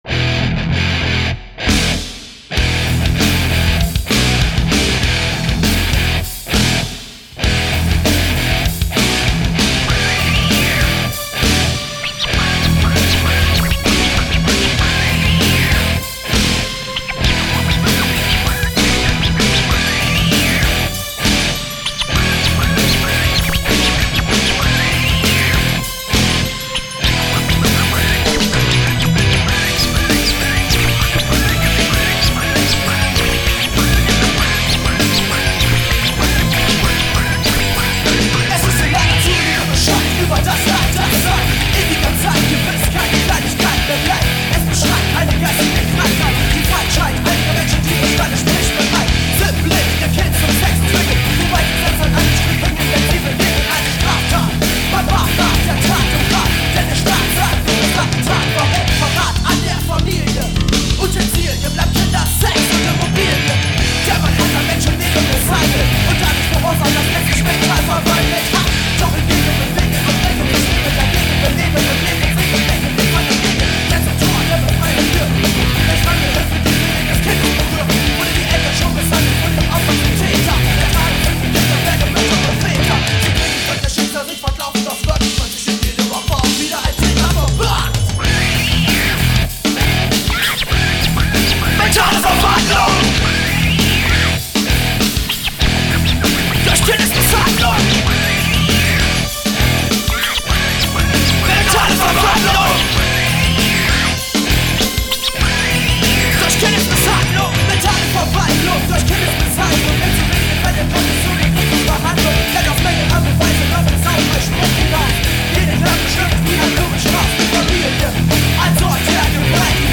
такие-то вертушки.